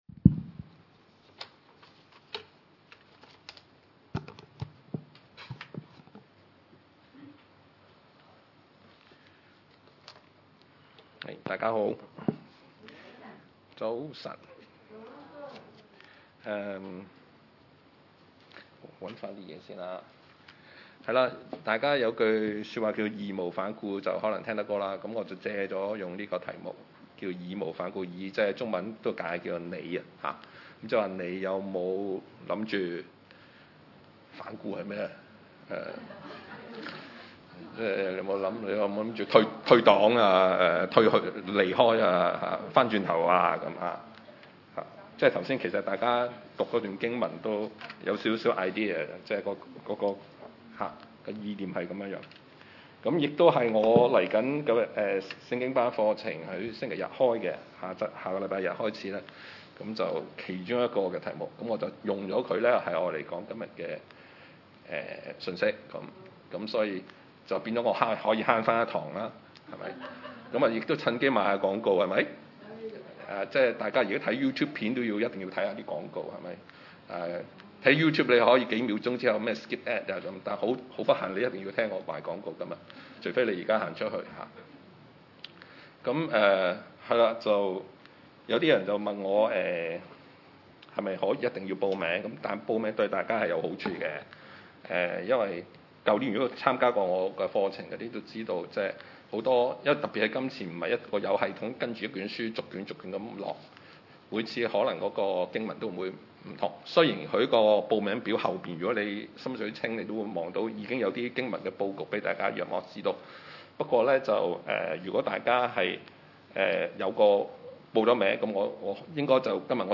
約翰福音 6:60-69 崇拜類別: 主日午堂崇拜 60 他 的 門 徒 中 有 好 些 人 聽 見 了 ， 就 說 ： 這 話 甚 難 ， 誰 能 聽 呢 ？